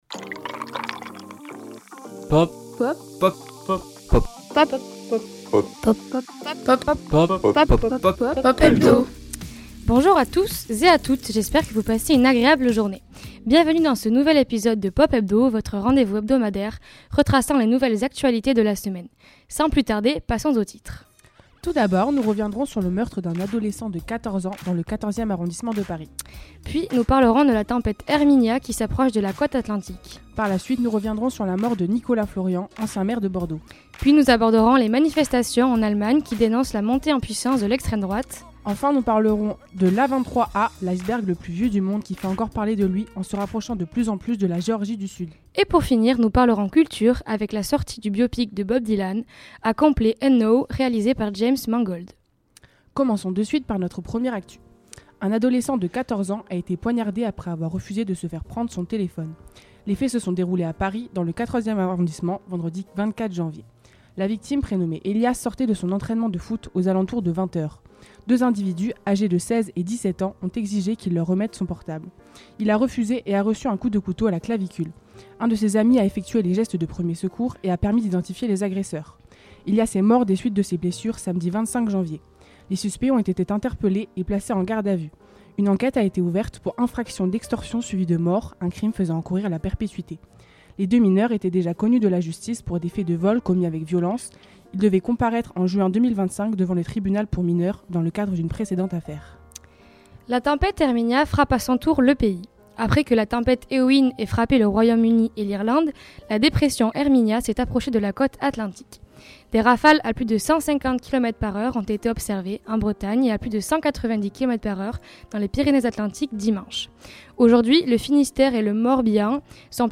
Programme à retrouver en direct tous les lundis entre 12h et 13h sur Radio Campus Bordeaux (merci à eux), et en rediffusion sur notre site Web et Spotify !